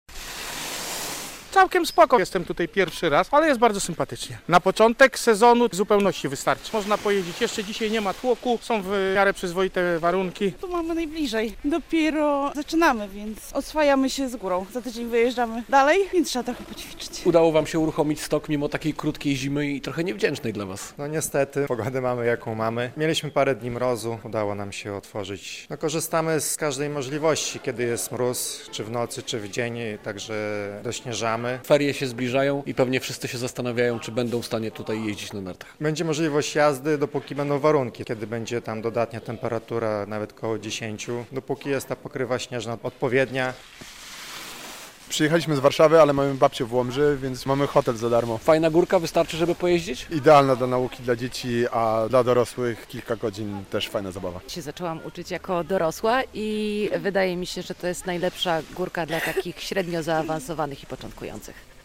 Przyjechaliśmy bo to bardzo dobry stok do nauki - mówili narciarze, z którym rozmawiał nasz dziennikarz.